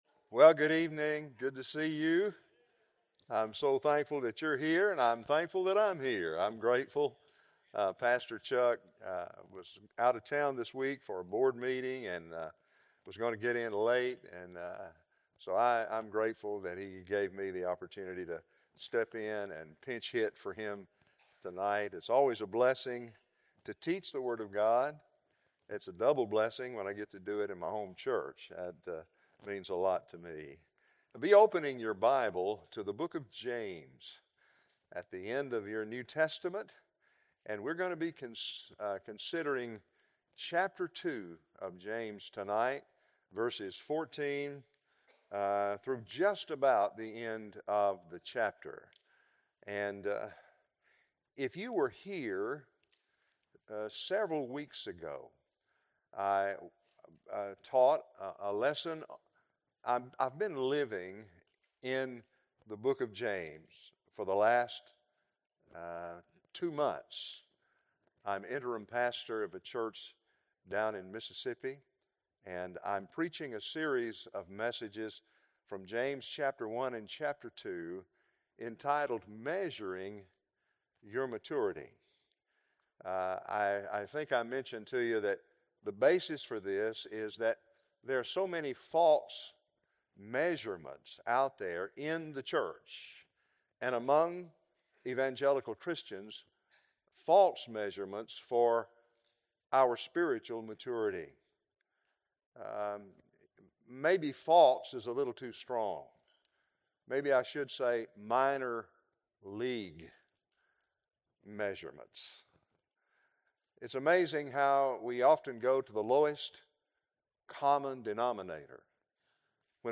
Wednesday Bible Study | March 4, 2026